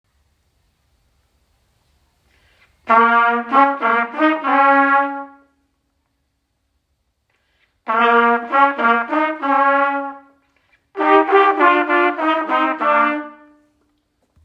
Trumpetti
Trumpetti.m4a